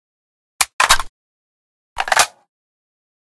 Divergent / mods / SVD Reanimation / gamedata / sounds / weapons / librarian_svd / reload.ogg
reload.ogg